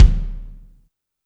INSKICK17 -L.wav